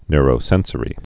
(nrō-sĕnsə-rē, nyr-)